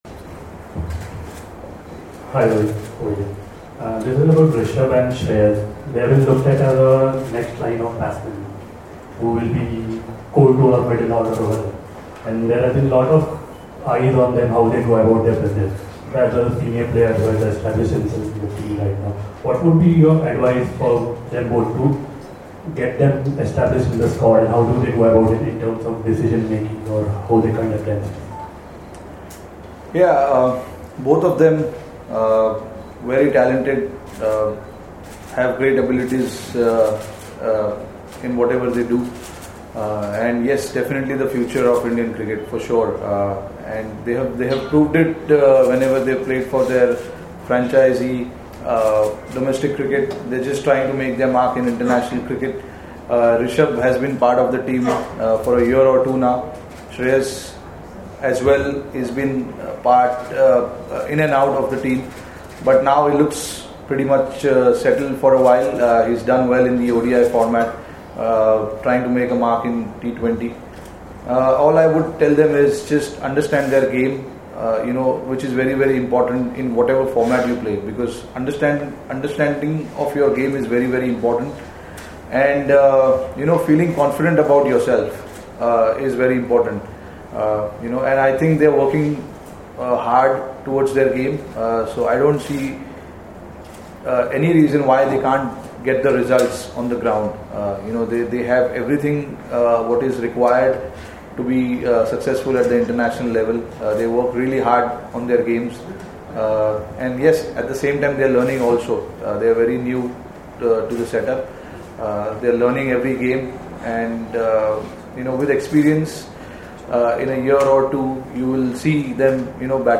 Rohit Sharma Captain spoke to the media in Nagpur on Saturday before the 3rd T20I against Bangladesh.